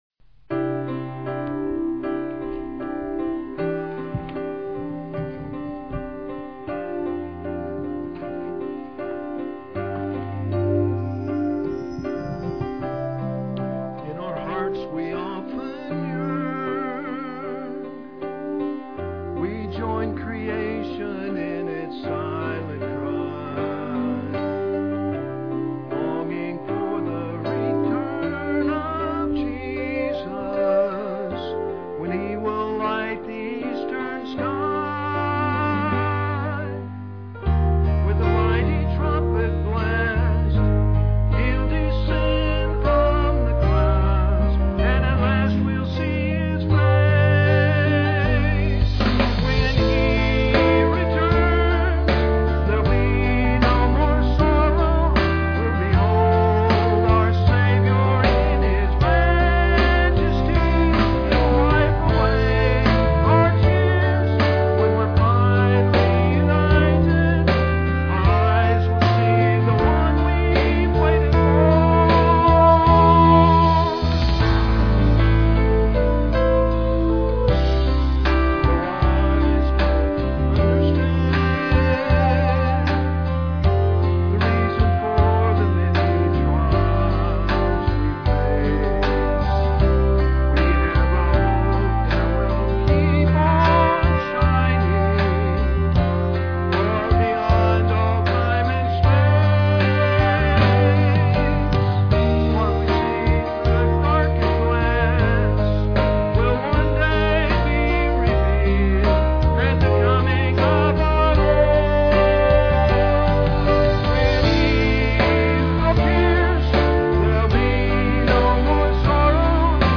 PLAY The Prophetic Word, Sep 10, 2006 Scripture: 2 Peter 1:19-21. Scripture Reading
solo.